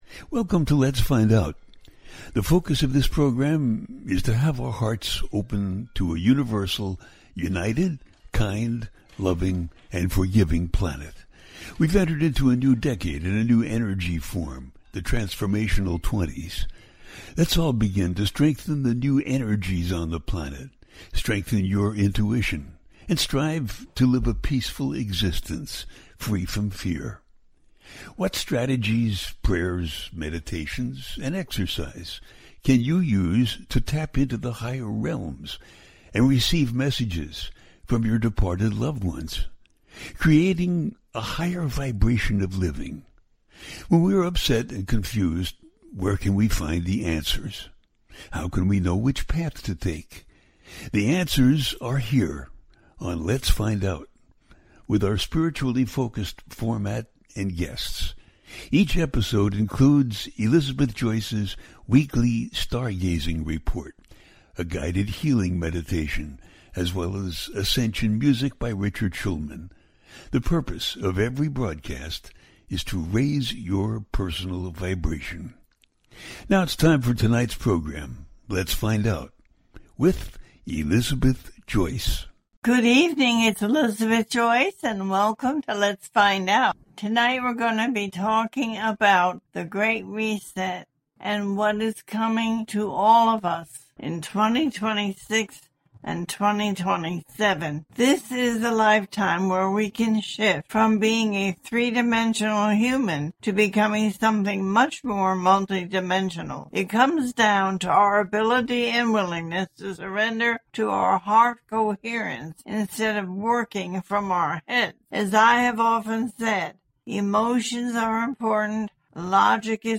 Ignite The 2026 Light Revolution - December 2025 Astrology - A teaching show
The listener can call in to ask a question on the air.
Each show ends with a guided meditation.